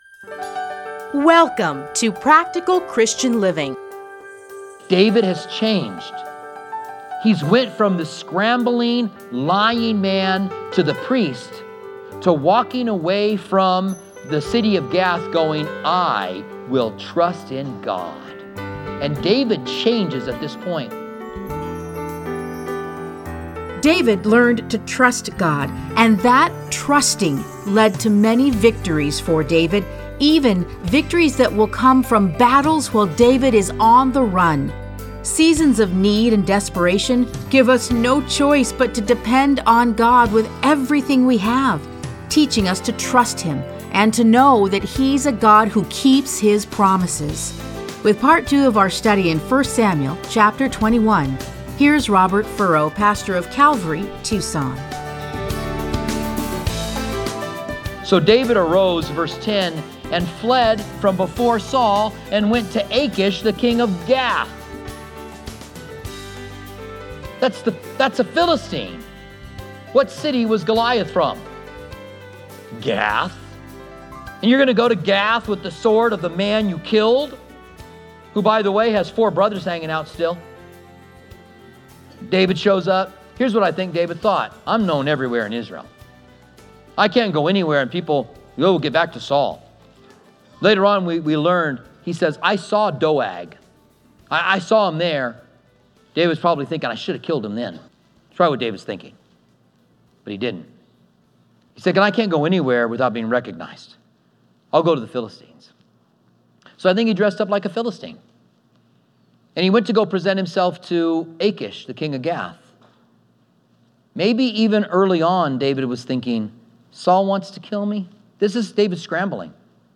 Listen to a teaching from 1 Samuel 21:1-15.